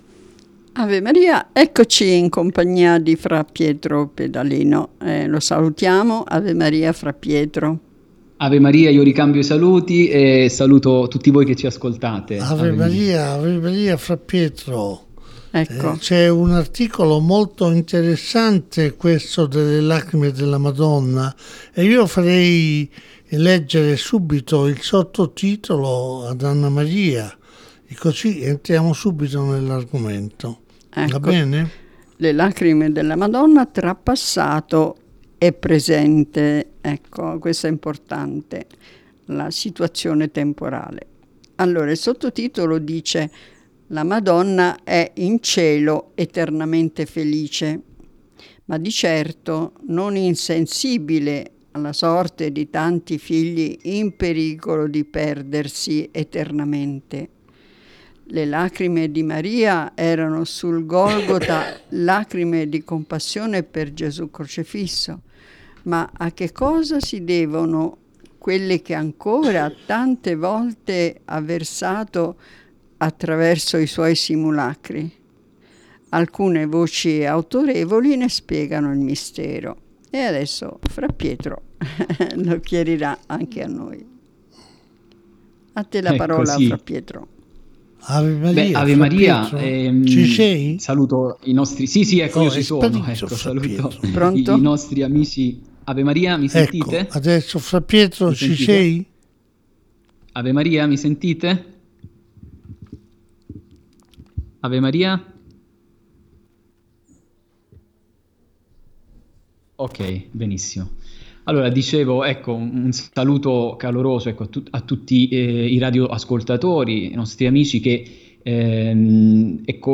In un mio intervento su Radio Buon Consiglio (giovedì 26 luglio), prendendo spunto da alcune recenti prodigiose lacrimazioni di statue dela Madonna avvenute in America Latina e in Spagna (casi ancora sotto esame e studio di equipe schientifiche) ho trattato del grande mistero delle lacrimazioni mariane, del loro significato, della loro attualità, delle conseguenti implicazioni nell’ambito della spiritualità e della teologia e della loro rilevanza profetica in relazione allo sfacelo mondiale che stiamo vivendo oggi.